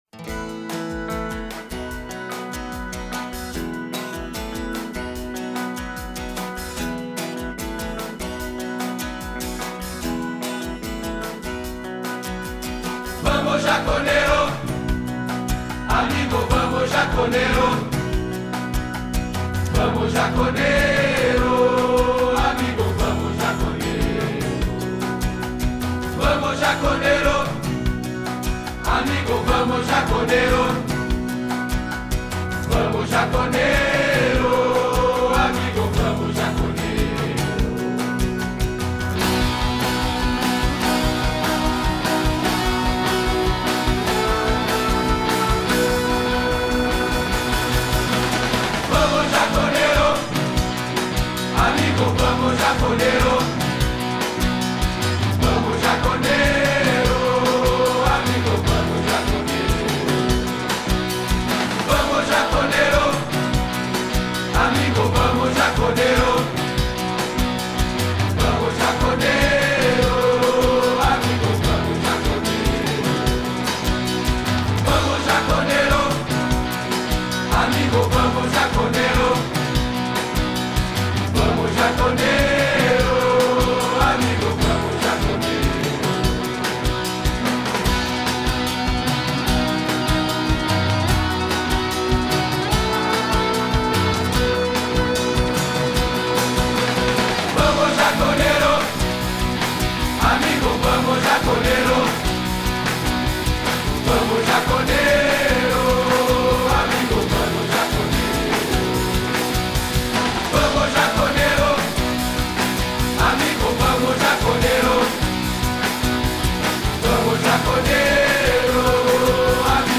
Hinos